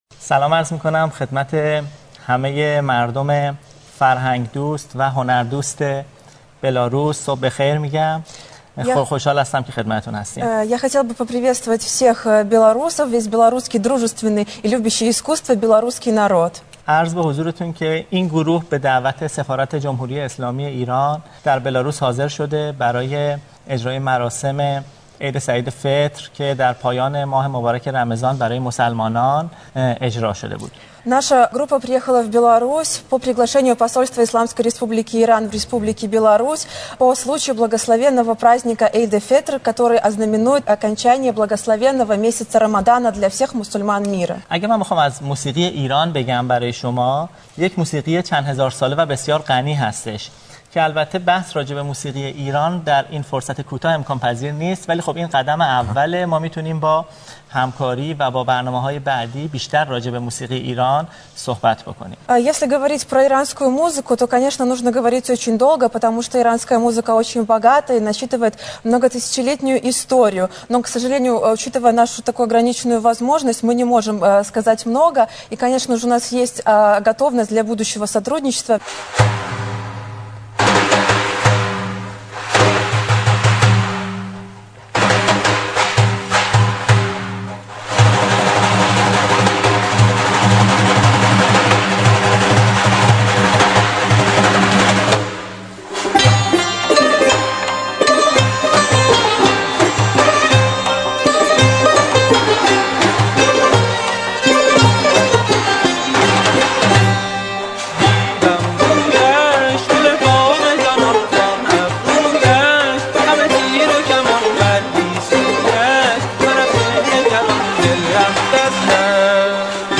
Один из таких концертов состоялся в Белорусском государственном экономическом университете. Слушатели смогли насладиться иранской музыкой, исполняемой на национальных инструментах.